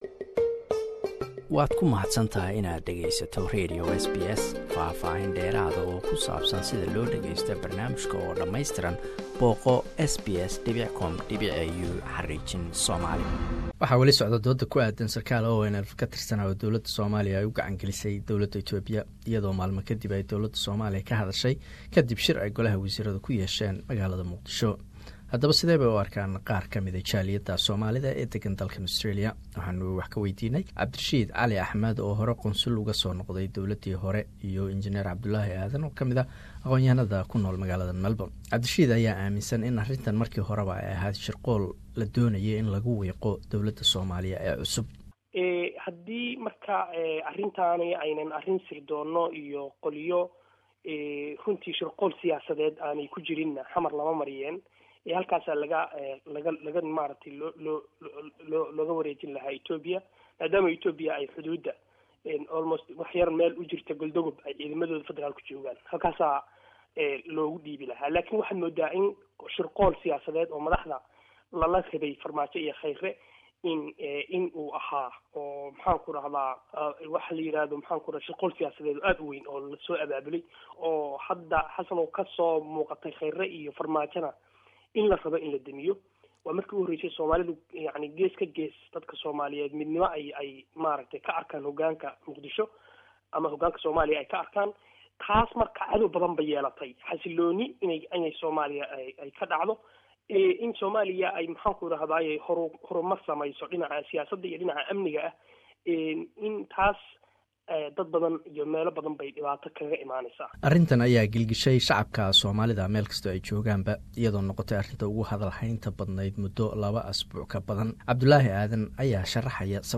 Waxaan ka waraysanay labo xubnood oo ka tirsan jaaliyada Soomalida Australia oo aragtidooda ka dhiibtay.